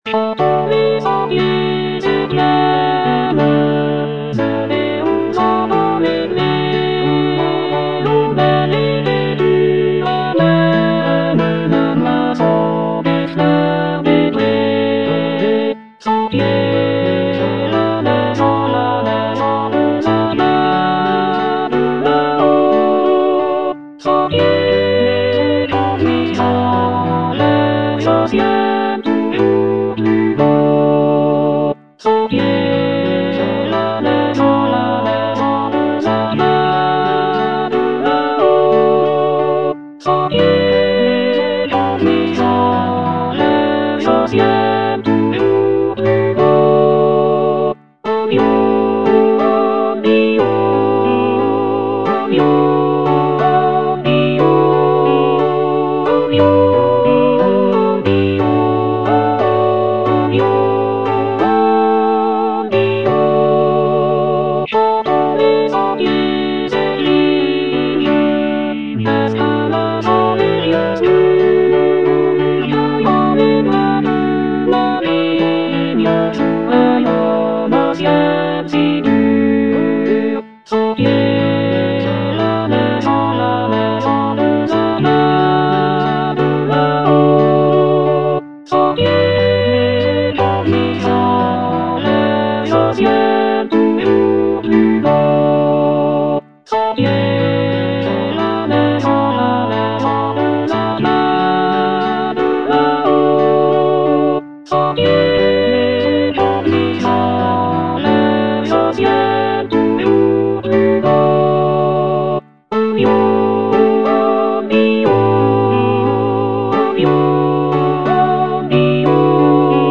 G. HAENNI - LES SENTIERS VALAISANS Alto (Emphasised voice and other voices) Ads stop: auto-stop
The piece is inspired by the picturesque landscapes and winding trails of the Valais region in Switzerland. Through its lush harmonies and lively melodies, the music captures the beauty and tranquility of the Swiss countryside, while also incorporating elements of traditional Swiss folk music. With its evocative soundscapes and dynamic rhythms, "Les sentiers valaisans" offers a musical journey through the breathtaking scenery of the Valais region.